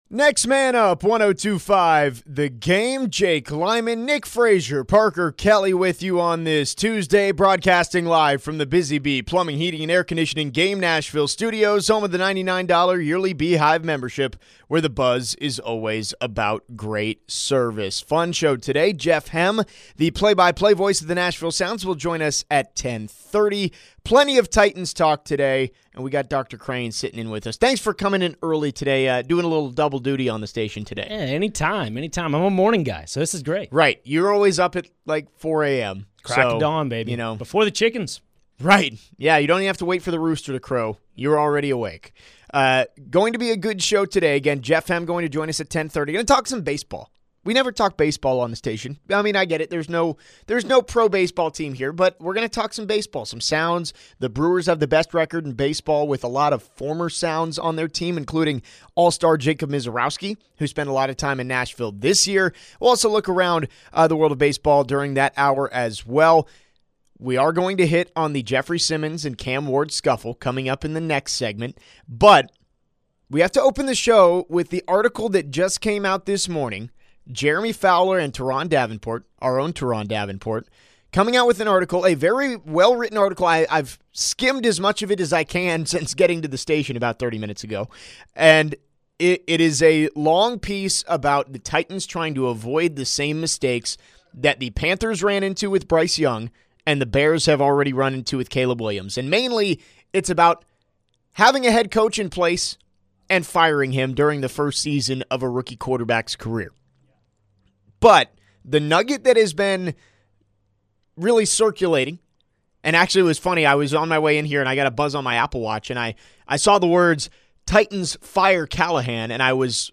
Plus, hear from Brian Callahan live from the podium just ahead of Titans prac...